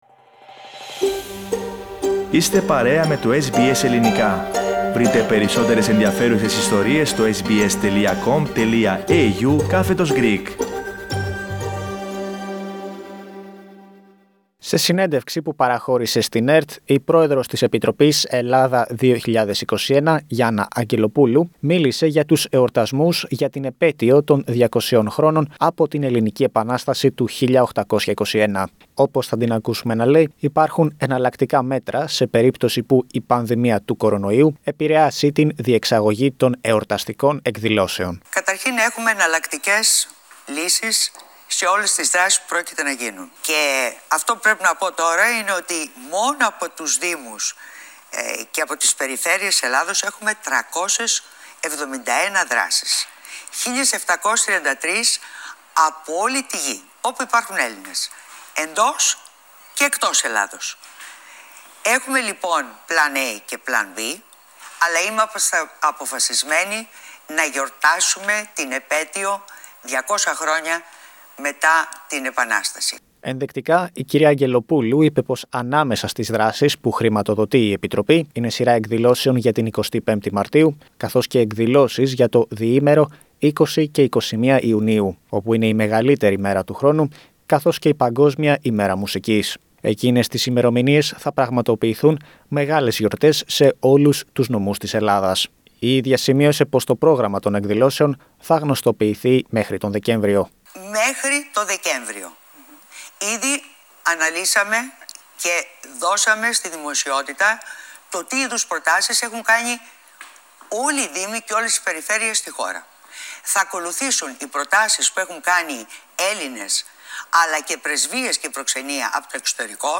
Για το χρονοδιάγραμμα των εορτασμών για την επέτειο 200 χρόνων από την Ελληνική Επανάσταση του 1821, μίλησε στην ΕΡΤ η πρόεδρος της Επιτροπής «Ελλάδα 2021» Γιάννα Αγγελοπούλου.